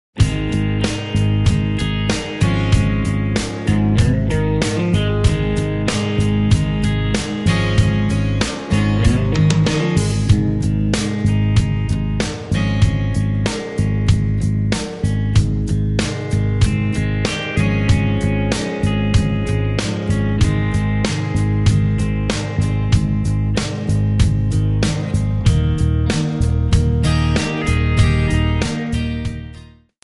Backing track files: Country (2471)
Buy With Backing Vocals.
Buy Without Backing Vocals